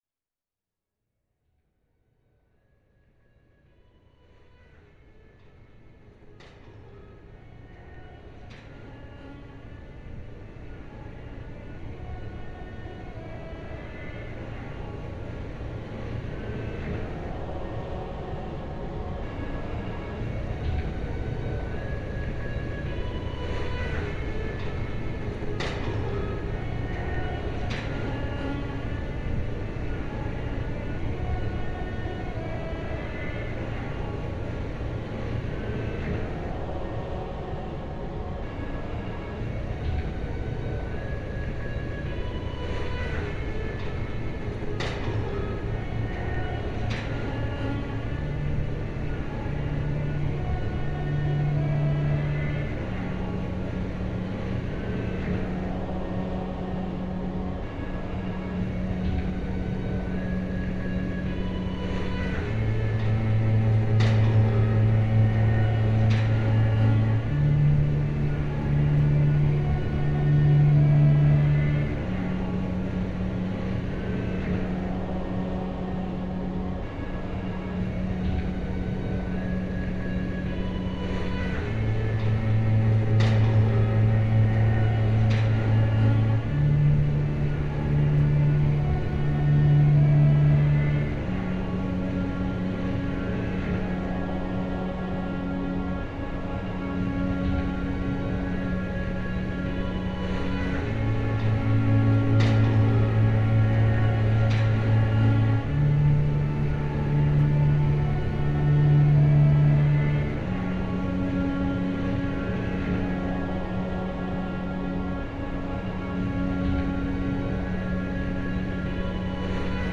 Ponte Raspi, Venice